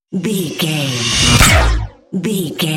Sci fi pass by shot
Sound Effects
futuristic
pass by
vehicle